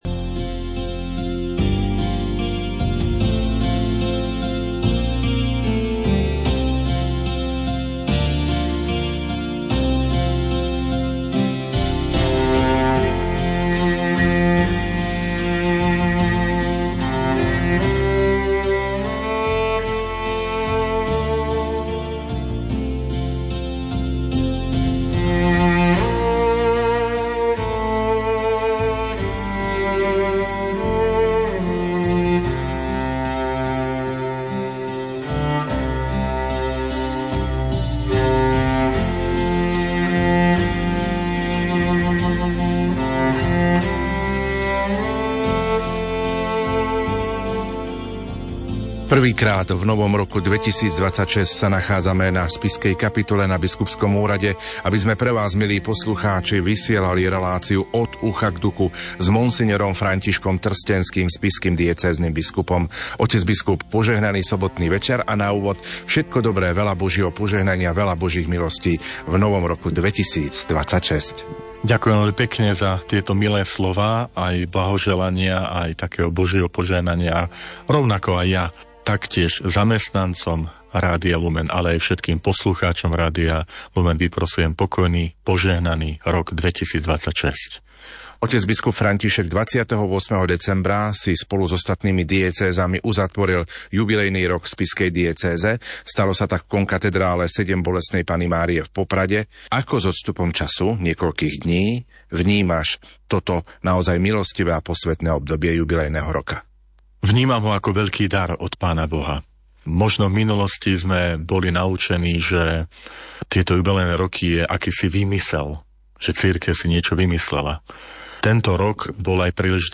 Aktuality zo života Spišskej diecézy. hosť: Mons. František Trstenský, spišský diecézny biskup